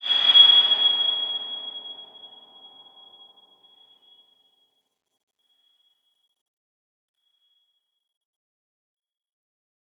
X_BasicBells-G#5-pp.wav